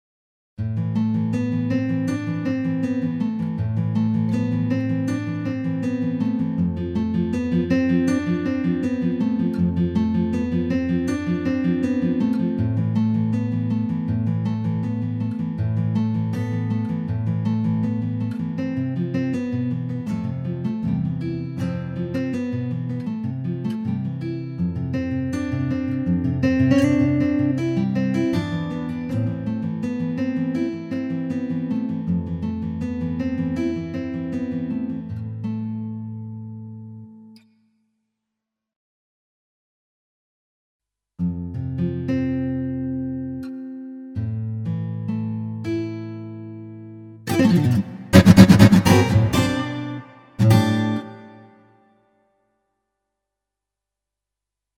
Вот гитарку на коленках наиграл с интегрой...